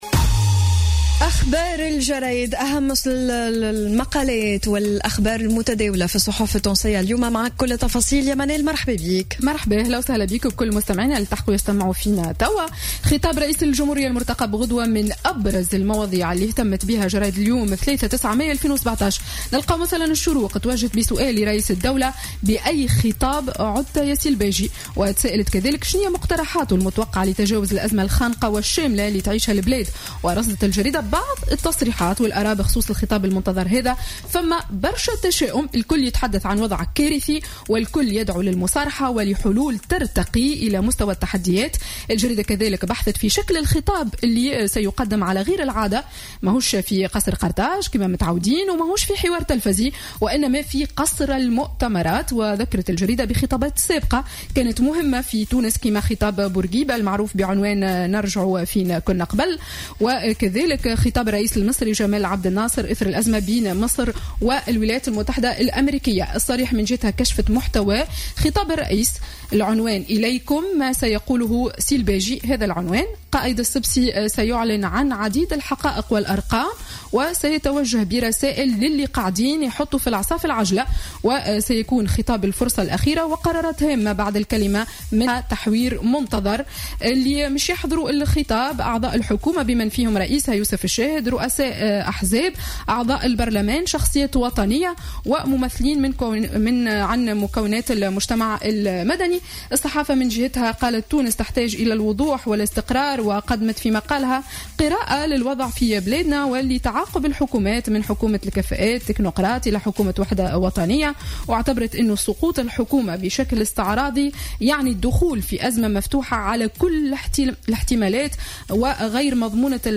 Revue de presse du mardi 09 mai 2017